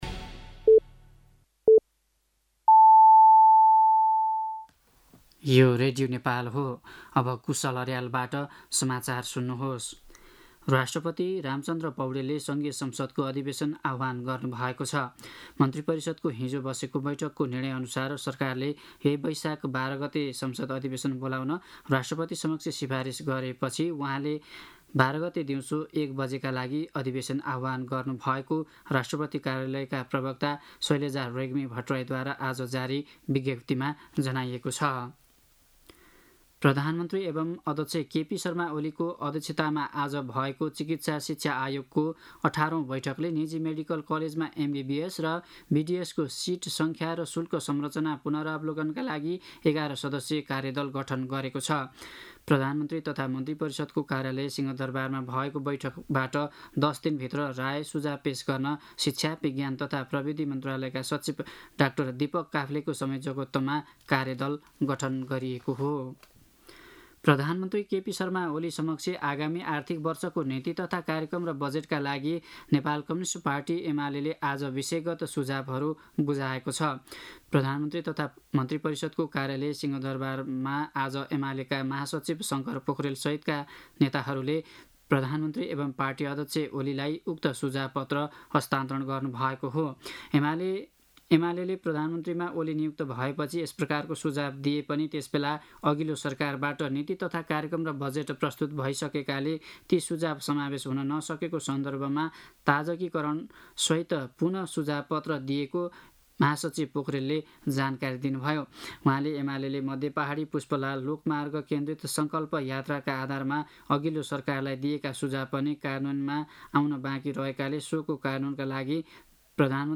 साँझ ५ बजेको नेपाली समाचार : ३ वैशाख , २०८२
5.-pm-nepali-news.mp3